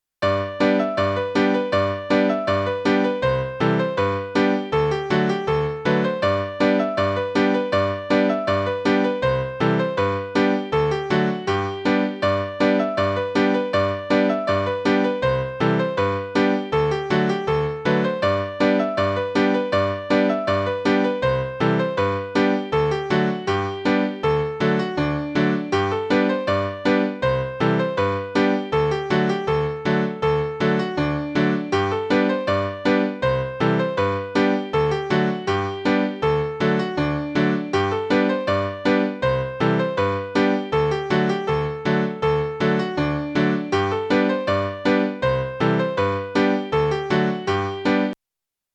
This is a suggested tune for the Lancashire Rose Dance.